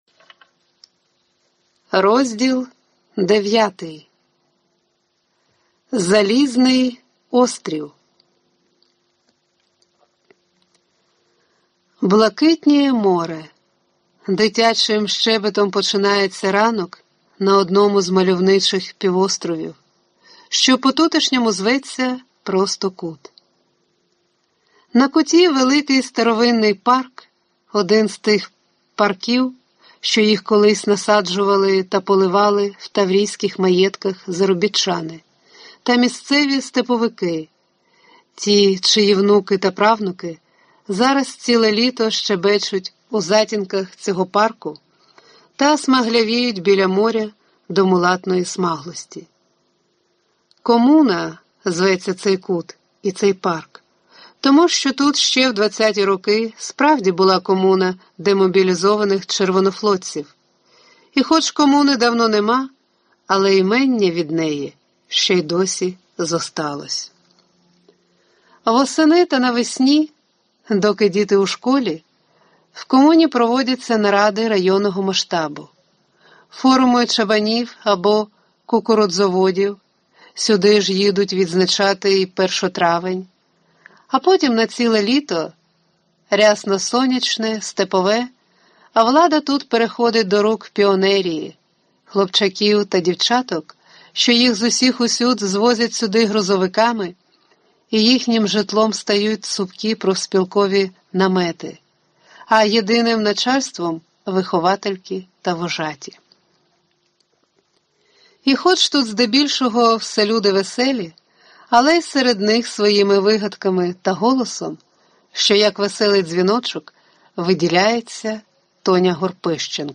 Аудиокнига Залізний острів. Новела | Библиотека аудиокниг